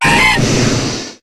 Cri de Crocrodil dans Pokémon HOME.